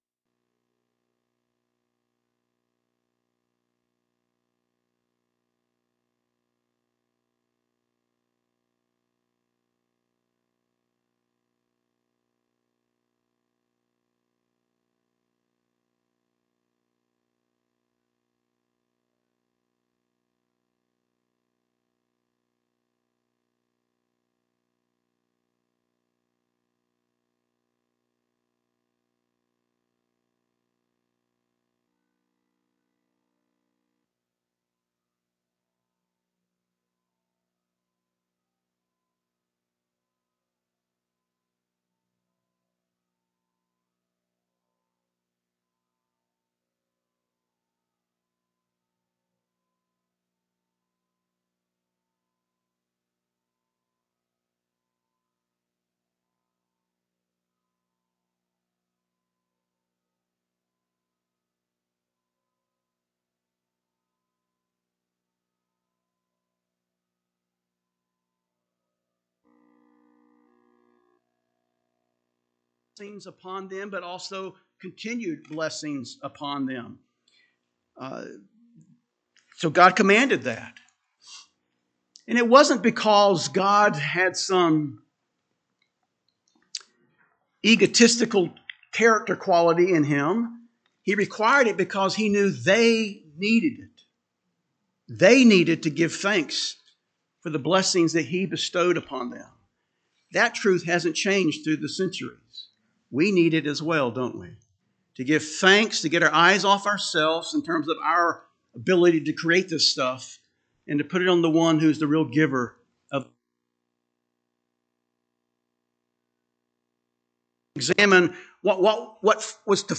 First couple minutes not recorded.